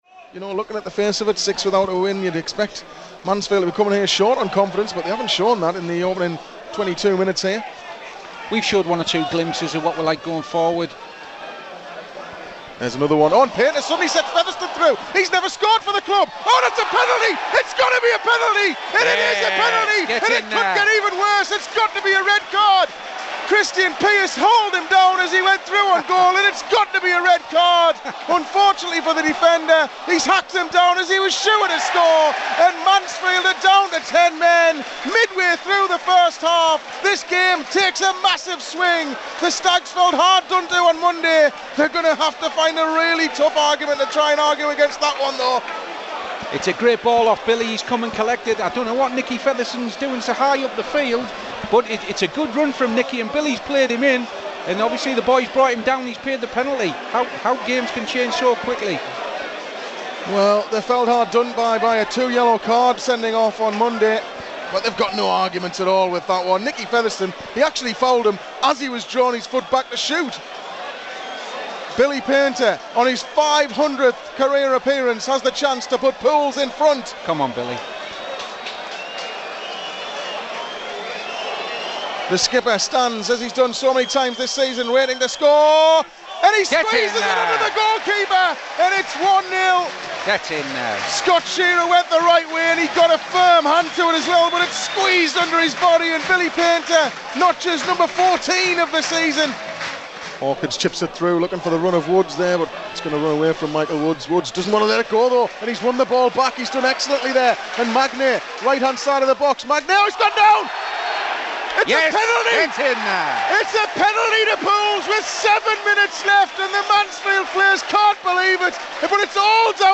Listen back to how the key moments from Saturday's game sounded as they happened live on Pools PlayerHD.